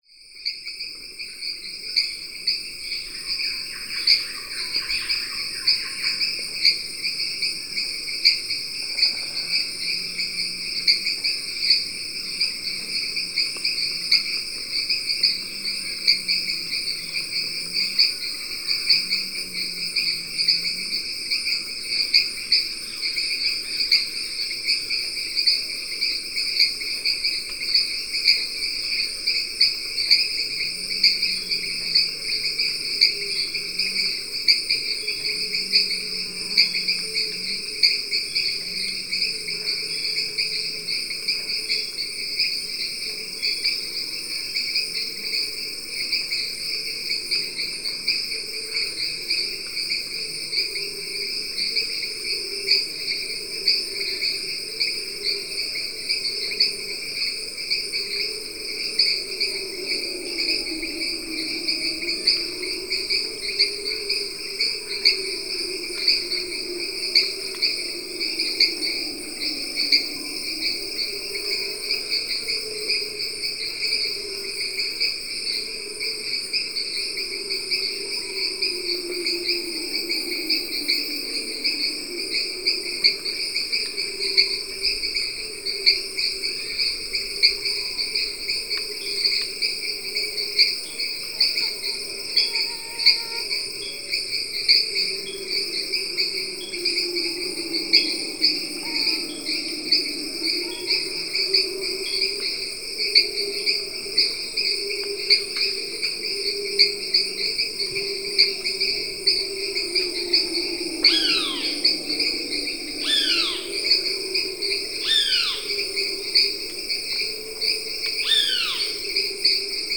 Monkeys howling in the Amazonian Rainforest
Category 🌿 Nature
Amazone ambiance ambiant atmosphere country Fear Field-recording Forest sound effect free sound royalty free Nature